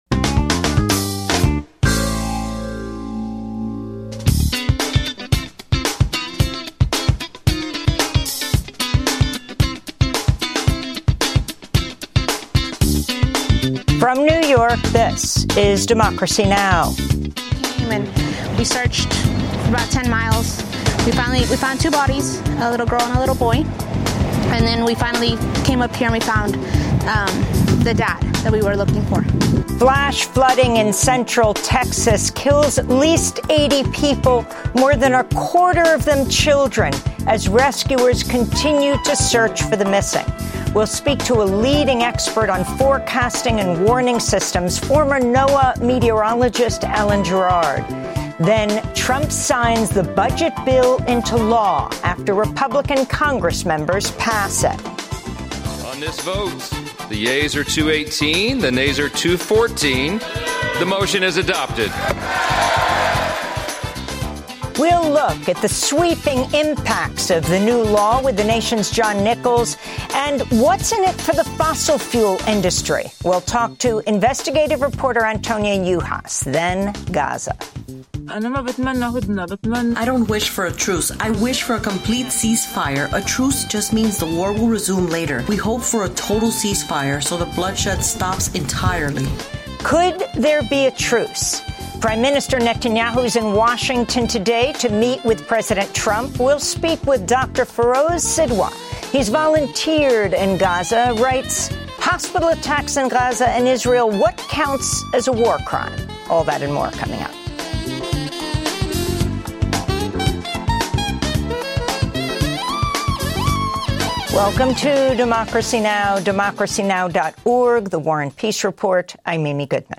daily, global, independent news hour